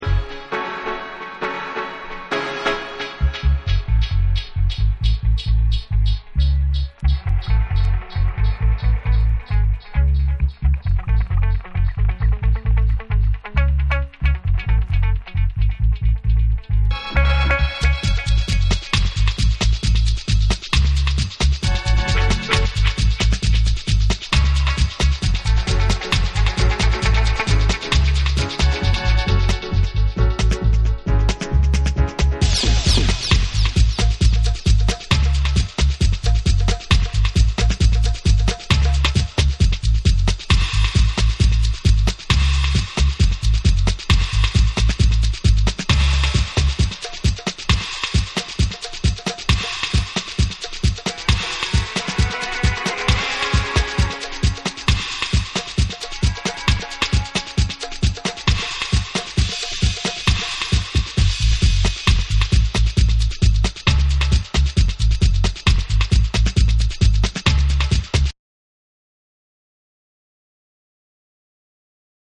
ヘビー・ウエイトなダブサウンドが楽しめる作品。
REGGAE & DUB